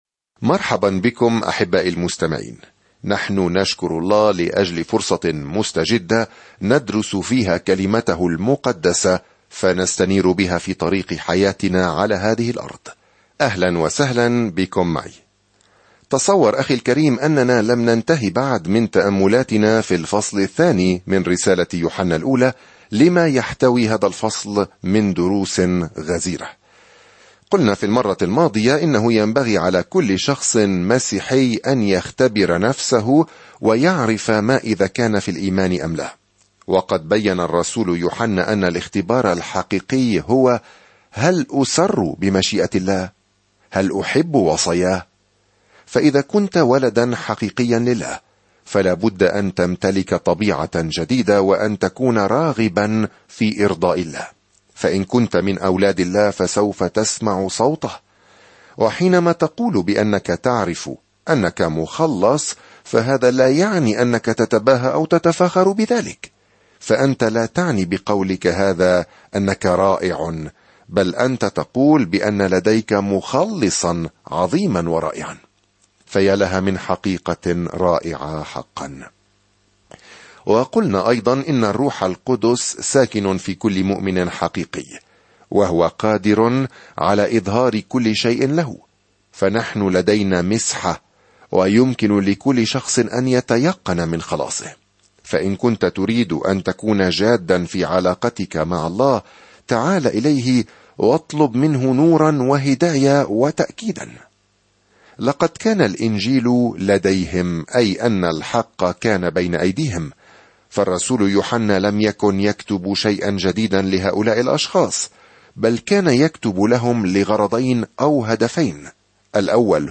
الكلمة يُوحَنَّا ٱلْأُولَى 26:2-29 يوم 10 ابدأ هذه الخطة يوم 12 عن هذه الخطة ليس هناك حل وسط في رسالة يوحنا الأولى هذه - إما أن نختار النور أو الظلمة، أو الحق أمام الأكاذيب، أو الحب أو الكراهية؛ نحن نحتضن أحدهما أو الآخر، تمامًا كما نؤمن بالرب يسوع المسيح أو ننكره. سافر يوميًا عبر رسالة يوحنا الأولى وأنت تستمع إلى الدراسة الصوتية وتقرأ آيات مختارة من كلمة الله.